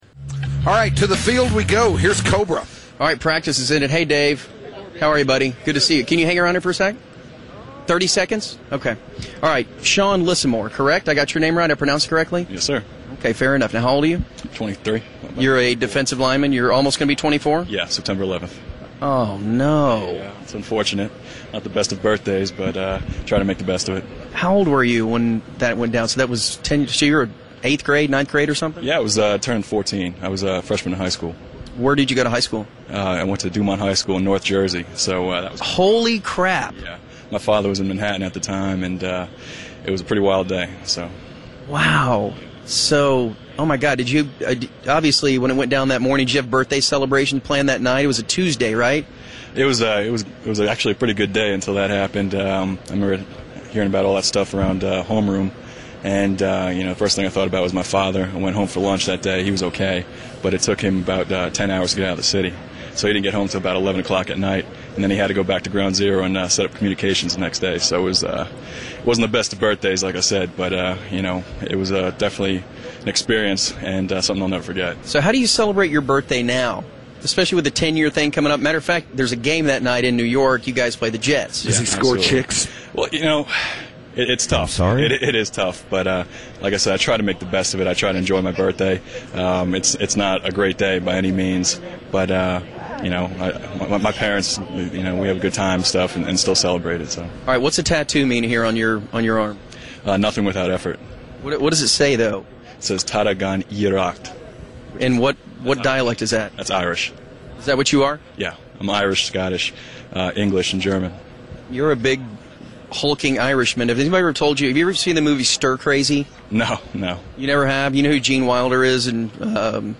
Cowboys Training Camp